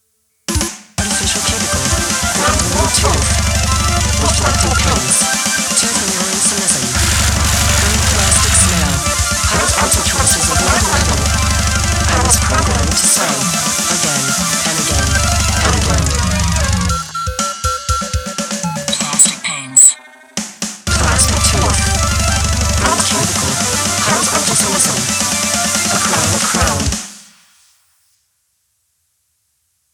grindcore, hardcore, experimental, midicore, chiptune, ,